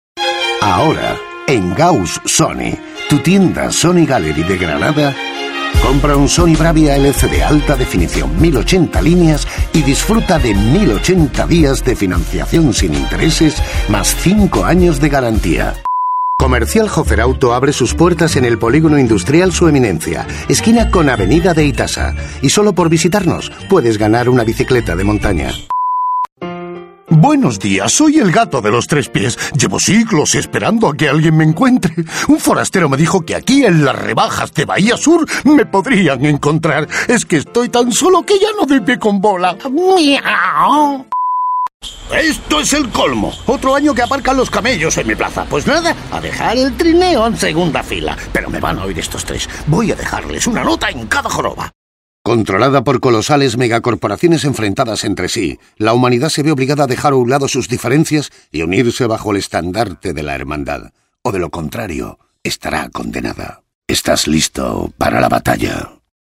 Voces Masculinas